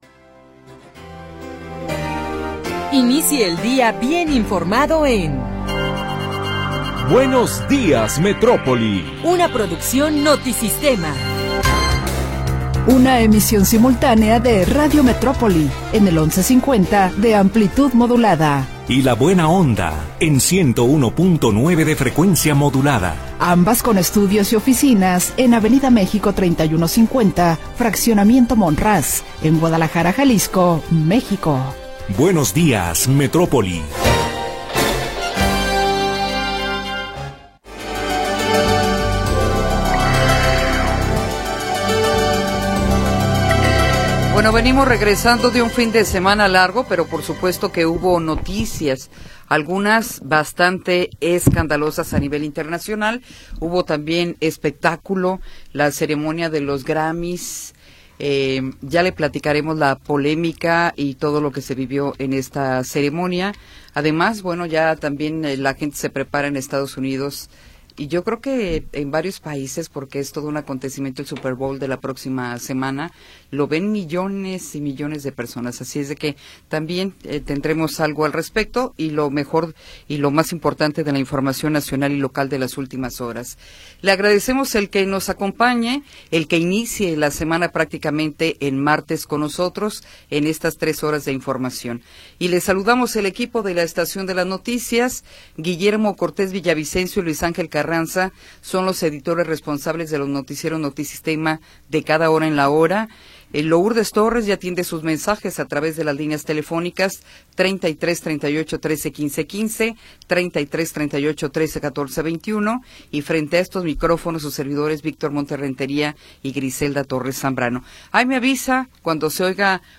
Primera hora del programa transmitido el 3 de Febrero de 2026.